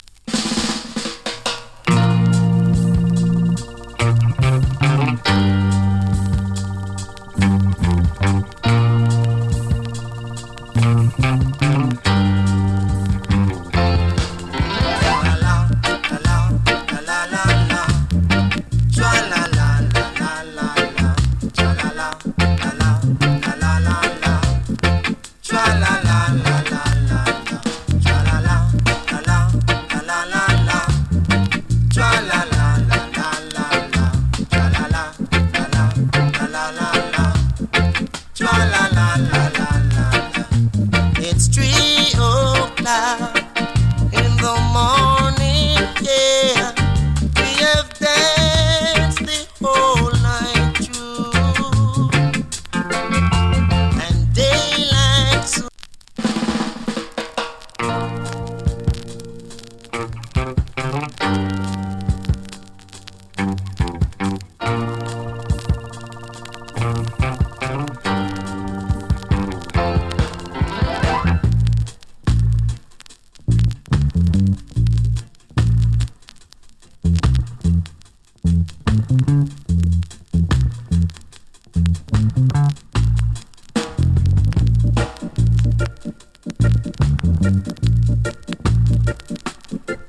チリ、ジリノイズ少々有り。
NICE VOCAL REGGAE ＋ VERSION.